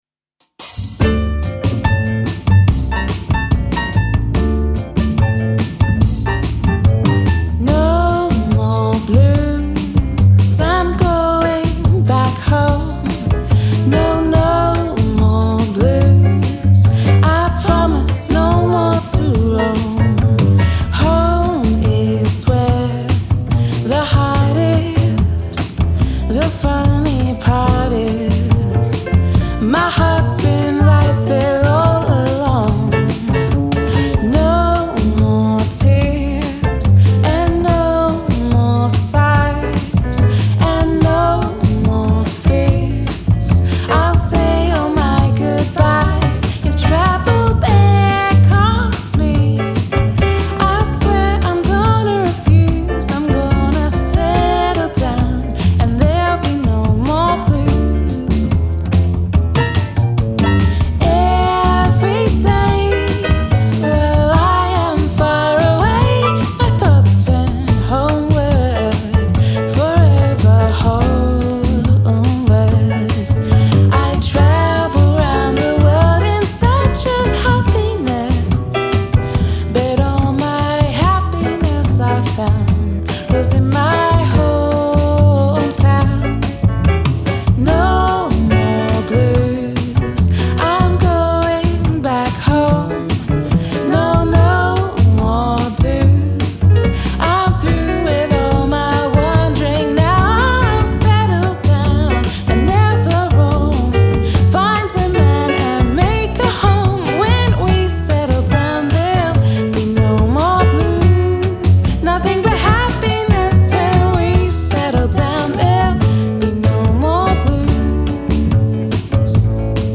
jazz & cultural